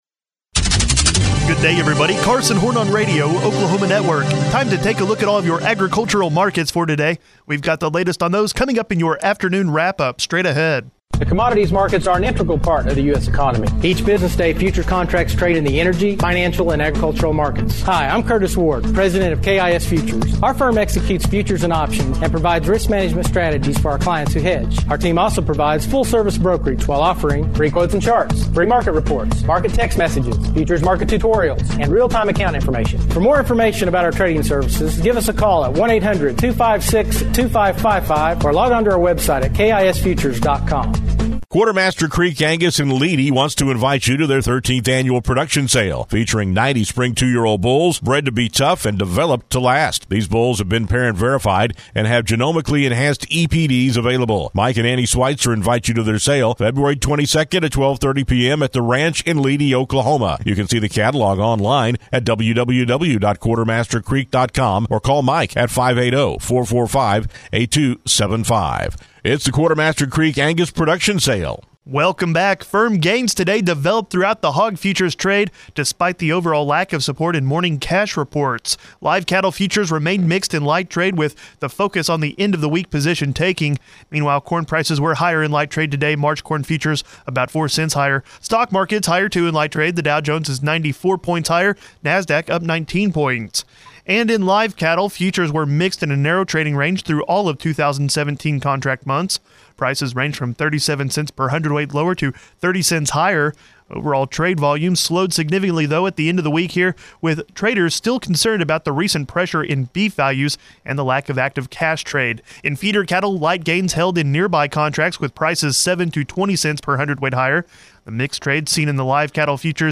the afternoon Wrap-Up of today agricultural markets